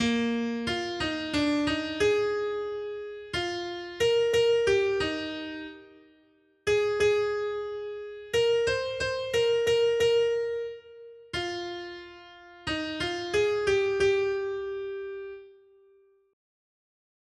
Noty Štítky, zpěvníky ol678.pdf responsoriální žalm Žaltář (Olejník) 678 Skrýt akordy R: Jak miluji tvůj zákon, Hospodine. 1.